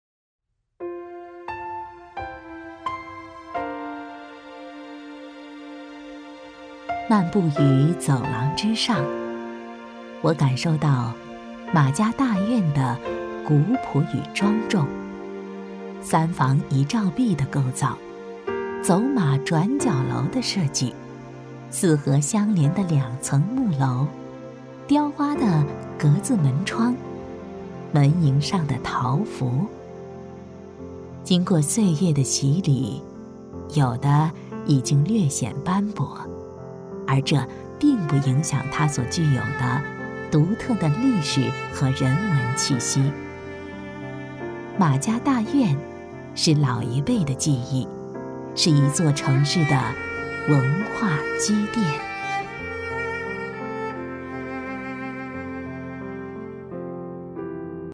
配音风格： 感情丰富，自然活力
【解说】马家大院